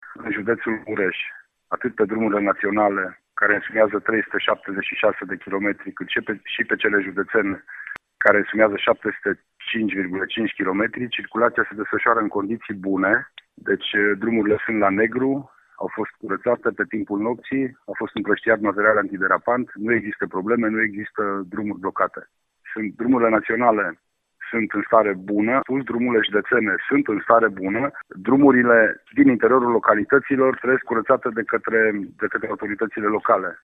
Prefectul județului Mureș, Lucian Goga, a declarat pentru RTM că în acest moment nu sunt drumuri blocate în județ, iar pe drumurile naționale și județene s-a intervenit eficient, astfel că stratul carosabil nu are zăpadă.